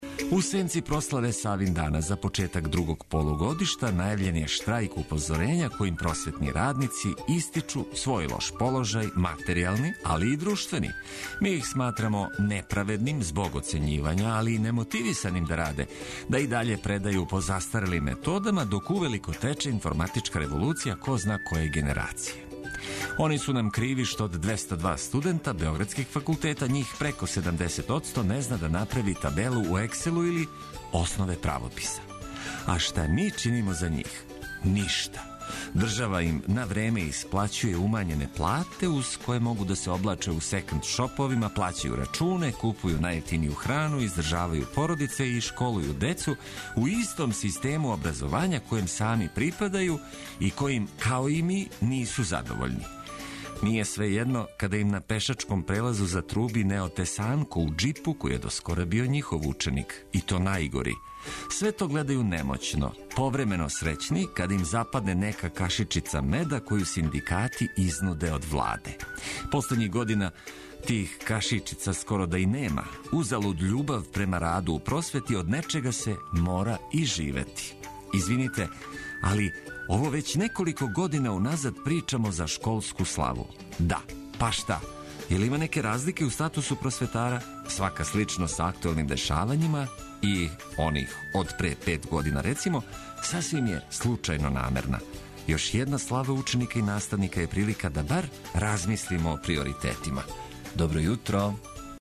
Уз веселу музику и добро расположење буђење и устајање и не пада тако тешко уз Београд 202.